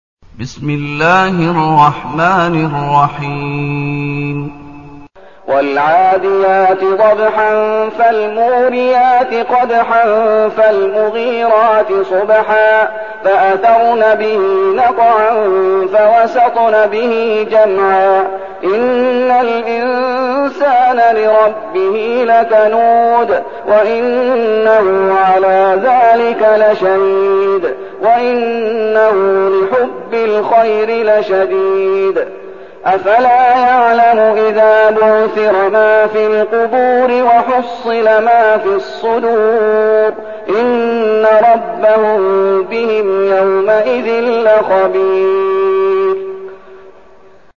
المكان: المسجد النبوي الشيخ: فضيلة الشيخ محمد أيوب فضيلة الشيخ محمد أيوب العاديات